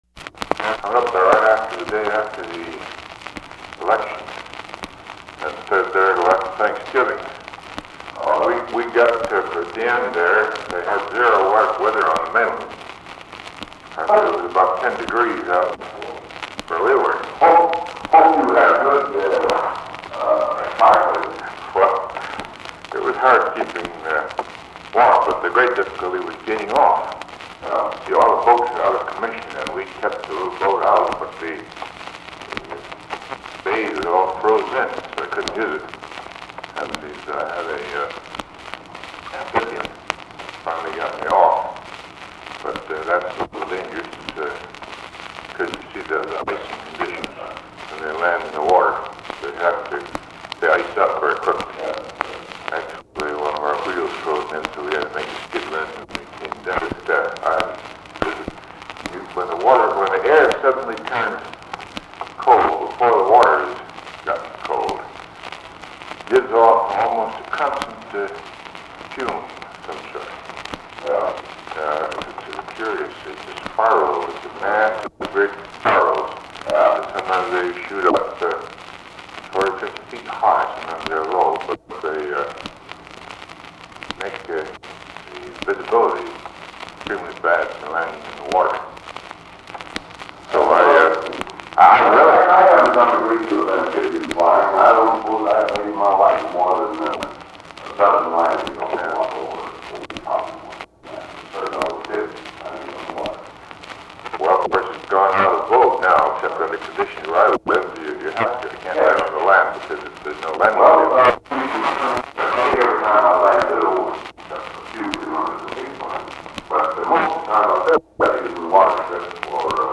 Recording begins after conversation had already begun. Eisenhower and Dulles are discussing amphibious landings of small aircraft.